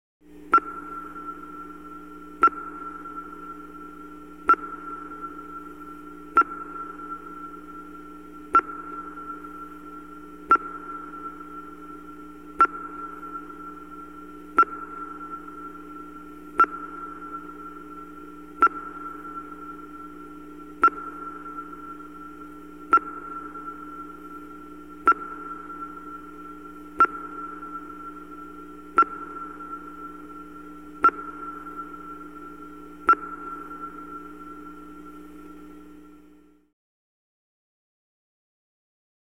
На этой странице собраны звуки сонаров подводных лодок — от монотонных импульсов до сложных эхолокационных сигналов.
Звук эхолокатора в космическом корабле